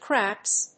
craps /krˈæps/
• / krˈæps(米国英語)